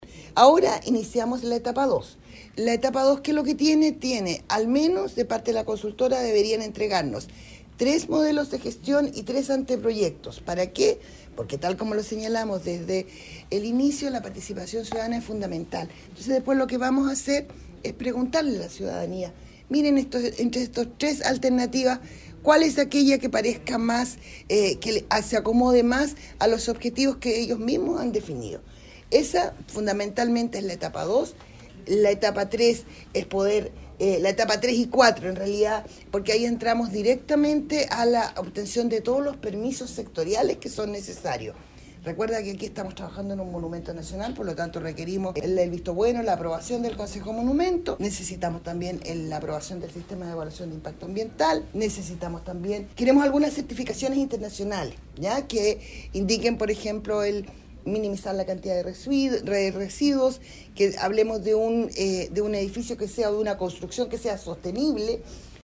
Por otra parte, la directora de Serviu Biobío, María Luz Gajardo, expresó que “en la etapa uno tenía dos objetivos primordiales, primero instalar el tema de la participación ciudadana en el marco del estudio del diseño del mercado y lo segundo era poder acceder al mercado y poder tener una valoración y una determinación de cómo estaba la estructura”.
MercadoCCP-3-DirectoraServiu.mp3